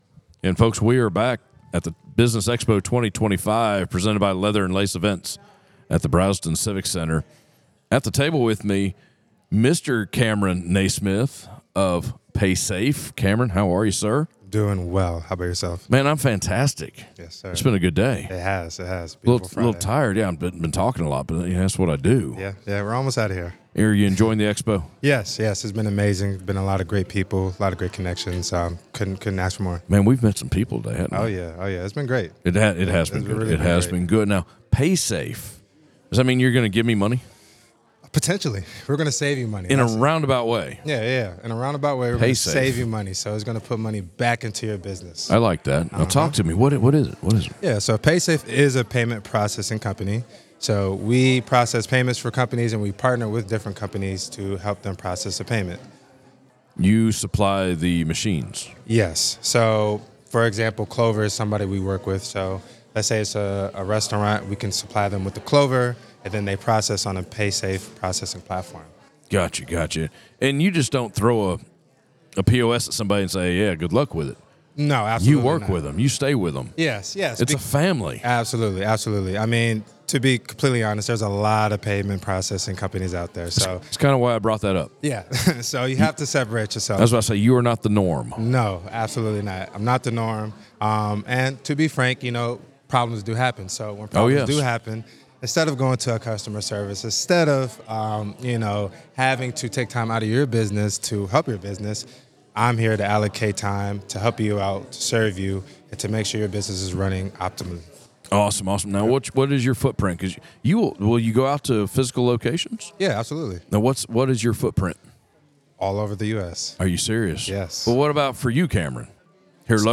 Business Expo 2025 presented by Leather & Lace Events at the Braselton Civic Center
Northeast Georgia Business RadioX – the official Podcast Studio of the Business Expo 2025